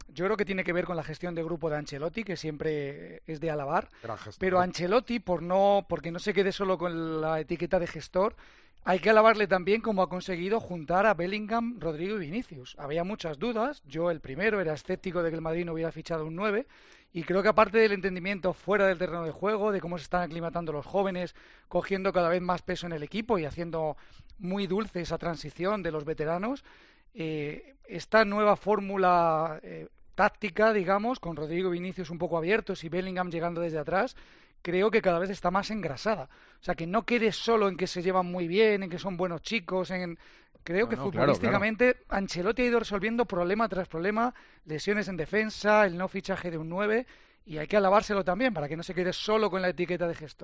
Son muchos los que en verano criticaban que el Real Madrid no hubiera fichado un delantero para reemplazar a Benzema y el tertuliano hizo esta reflexión en El Partidazo de COPE